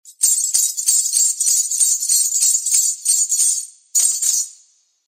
Danza árabe, bailarina mueve las tobilleras de monedas 02
Sonidos: Acciones humanas